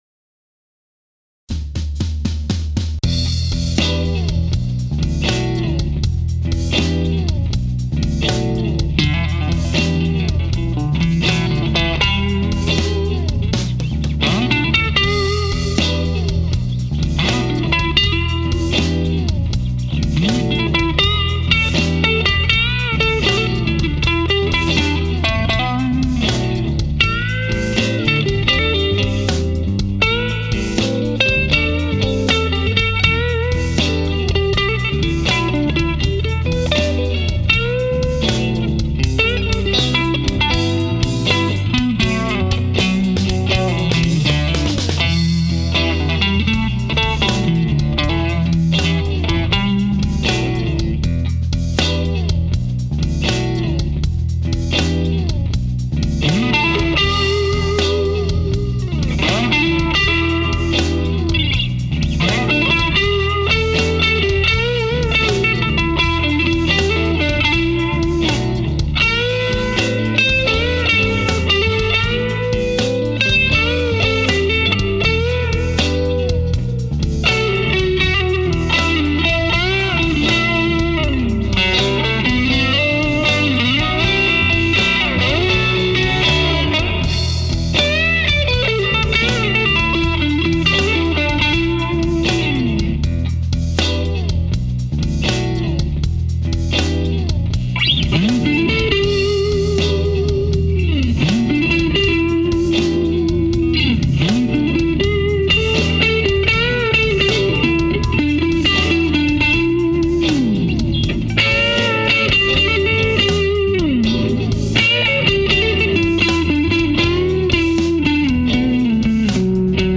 I was noodling around the other day, and came up with a riff.
• Rhythm Part: Clean Strat in Neck/Middle position. My Aracom VRX22 in the Clean channel, with the Master cranked and volume at halfway. Used a Red Bear Trading Tuff-Tone pick to get that percussive sound out of the chords.
• Part 1 Solo: Strat in Neck Position into my MicroVibe and the same amp settings. Also, used the Tuff-Tone pick to get a more percussive attack to the notes.
Amp was set on Channel 2 with the Master dimed and volume at 6 for some nice, but not over-the-top breakup.
• Part 3 Solo: Strat in Neck position, nixed the Vibe, into the clean channel with Master and Volume fully dimed. Used the Psycho here as well, but used a percussive attack.